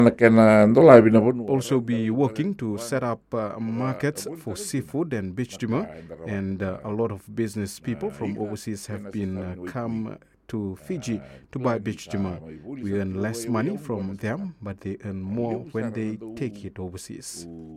Prime Minister Sitiveni Rabuka speaking during Radio Fiji One’s “Na Noda Paraiminisita”